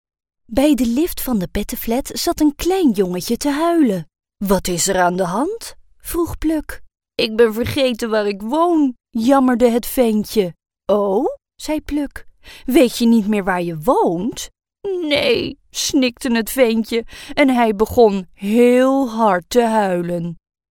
Recording in our own professional studio, we deliver quality clean and crisp tracks.
Sprechprobe: Sonstiges (Muttersprache):
Smooth, professional, playful, natural, Dutch.